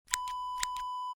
Scissors snip sound effect .wav #7
Description: The sound of a pair of scissors snipping
Properties: 48.000 kHz 16-bit Stereo
A beep sound is embedded in the audio preview file but it is not present in the high resolution downloadable wav file.
Keywords: scissors, snip, snipping, cut, cutting, hair, click, clicking
scissors-snip-preview-7.mp3